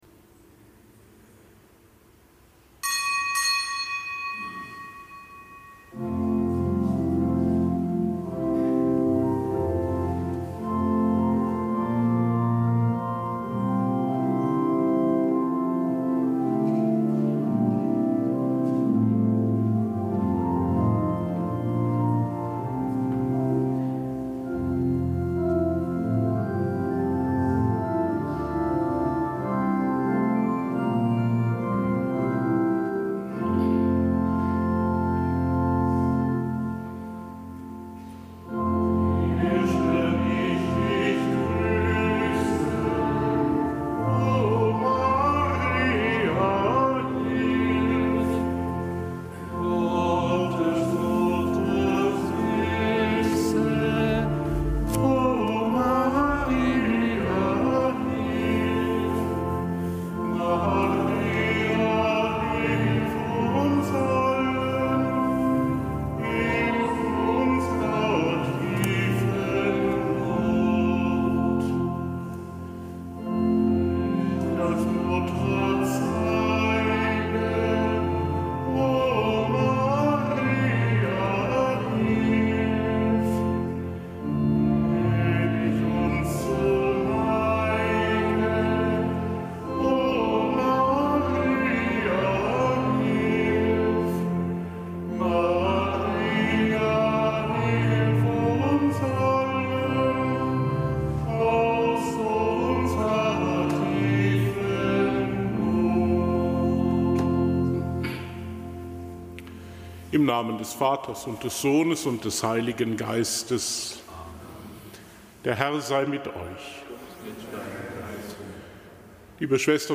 Kapitelsmesse am Samstag der vierten Woche im Jahreskreis